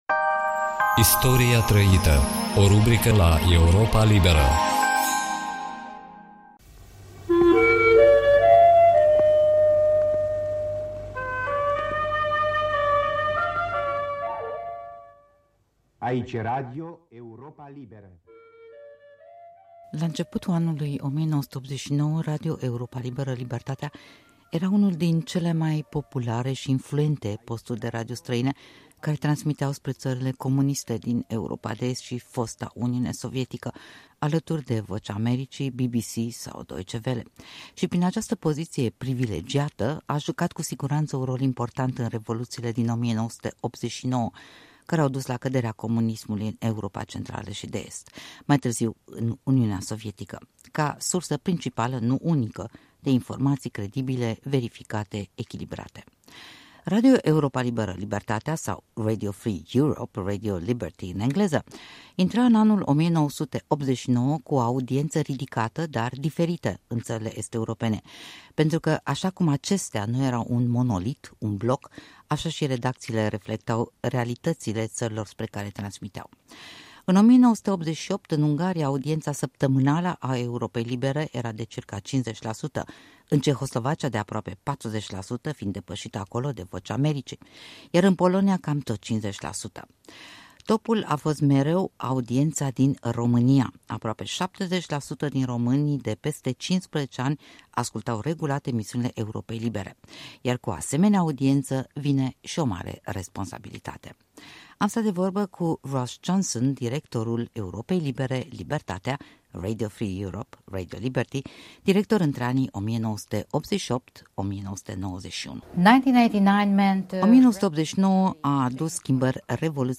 Radio Europa Liberă/Libertatea și revoluțiile din 1989 (interviu